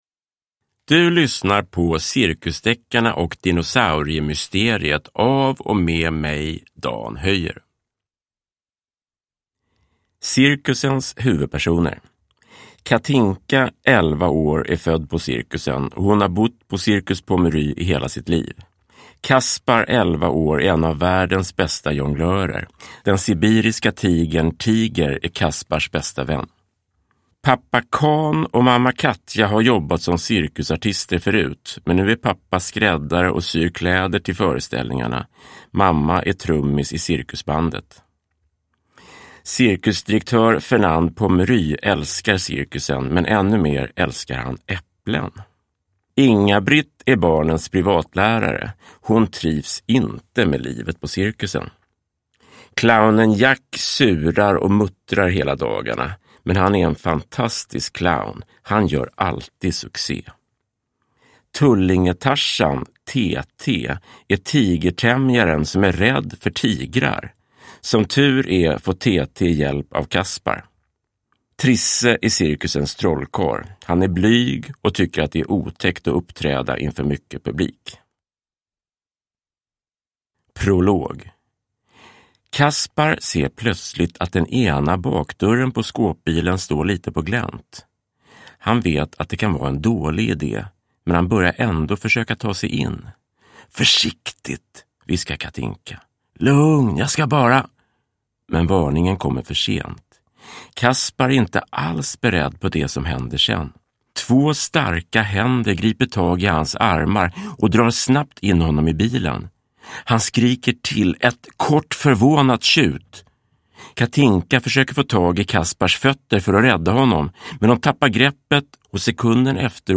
Cirkusdeckarna och dinosauriemysteriet – Ljudbok